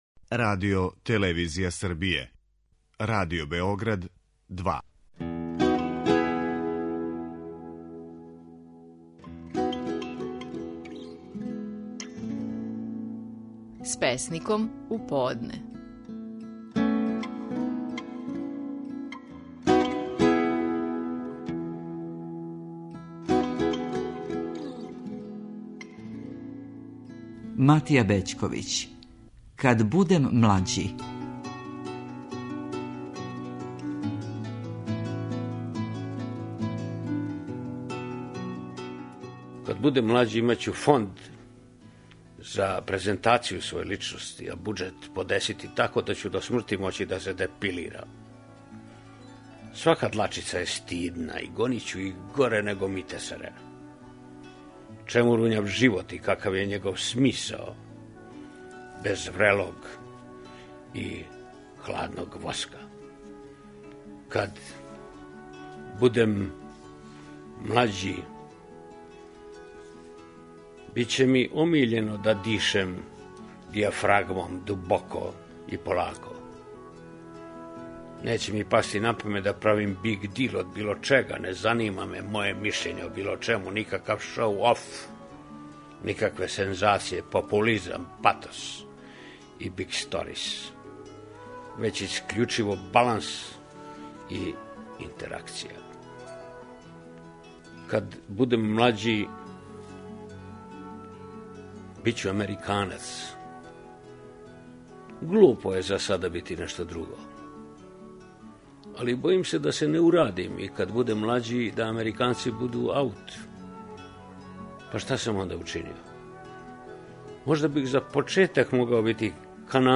Наши најпознатији песници говоре своје стихове
Матија Бећковић говори песму „Кад будем млађи".